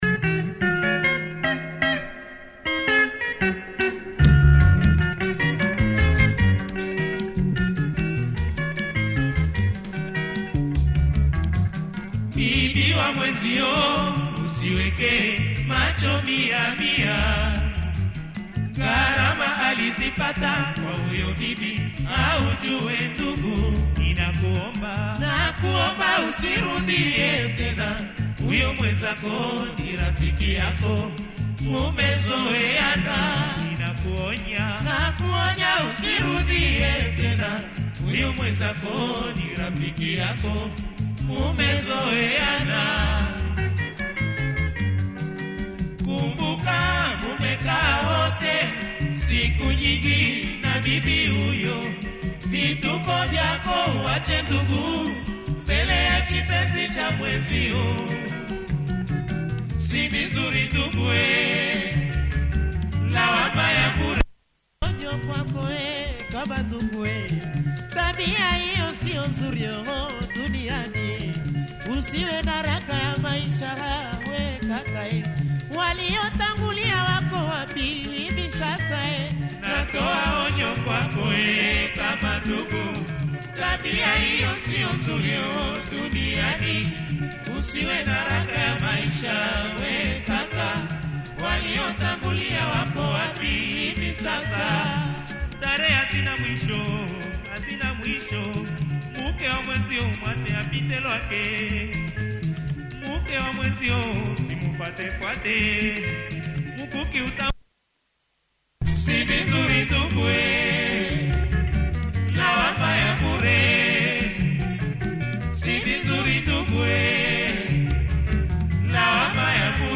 anyways good production, changes pace mid-way